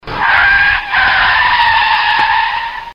auto frenando freno Meme Sound Effect
auto frenando freno.mp3